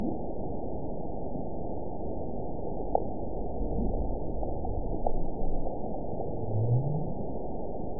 event 922075 date 12/26/24 time 05:15:55 GMT (11 months, 1 week ago) score 9.57 location TSS-AB03 detected by nrw target species NRW annotations +NRW Spectrogram: Frequency (kHz) vs. Time (s) audio not available .wav